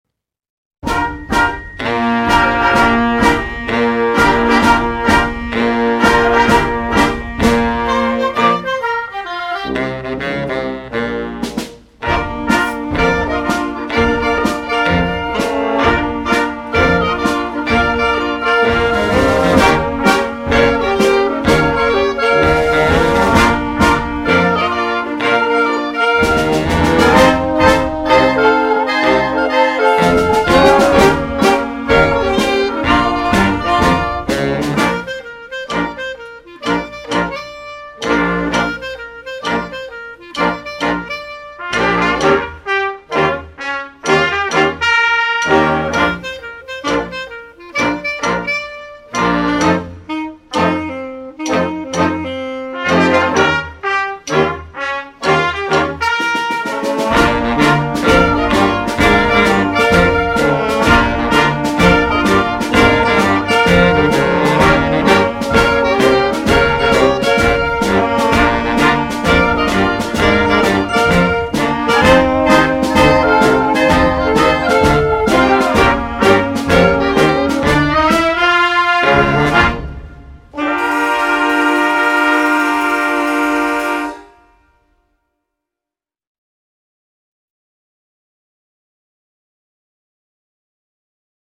Concert Band
Boogie Woogie style